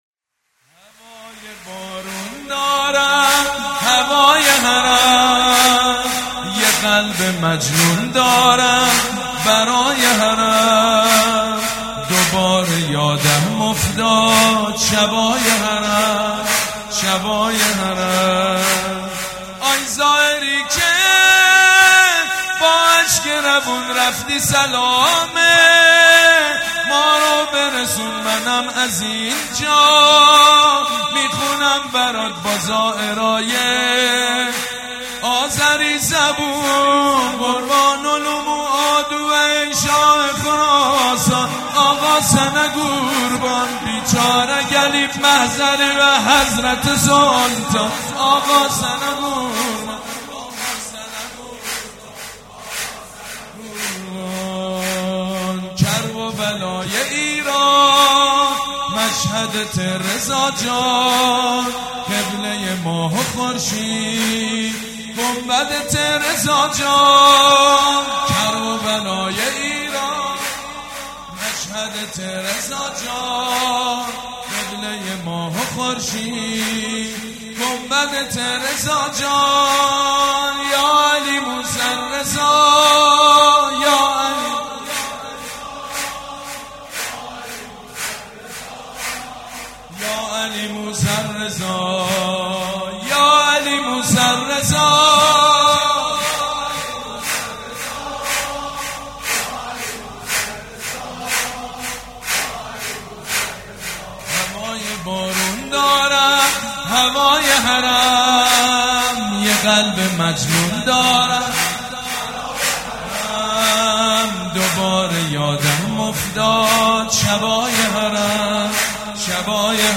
«میلاد امام رضا 1397» سرود: هوای بارون دارم هوای حرم